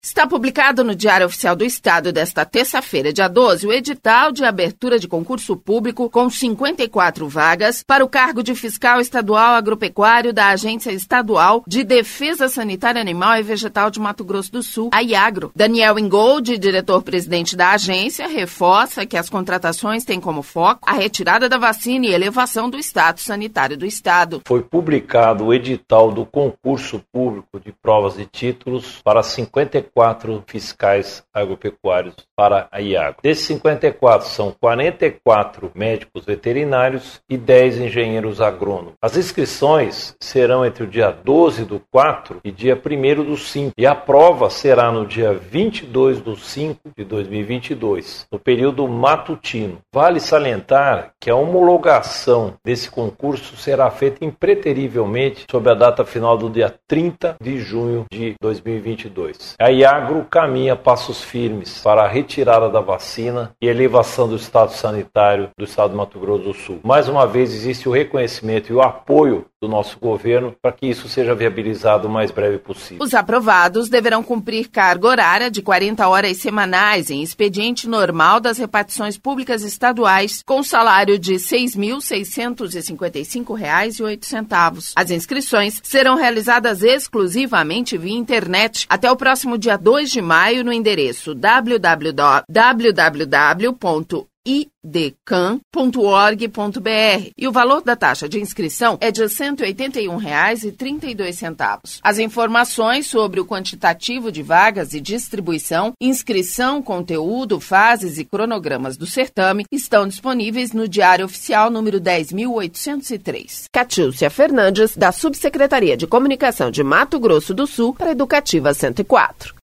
Confira outros detalhes na reportagem